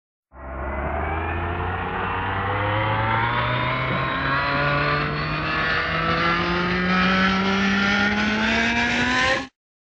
BSG FX - Viper Blast Off Whine - Building
BSG_FX_-_Viper_Blast_Off_Whine_-_Building.wav